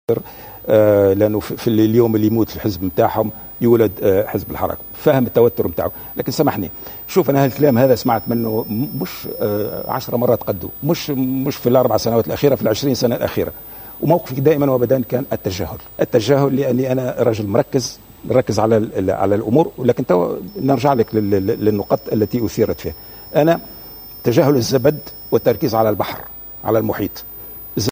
وأضاف المرزوقي، ضيف برنامج "شكرا على الحضور" أمس الاثنين على القناة الوطنية الأولى في ردّه على تصريحات الناطق باسم حزب نداء تونس، بوجمعة الرميلي بخصوص تكوينه حزبا جديدا " أتفهم توتّره...موقفي دائما وابدأ كان التجاهل..".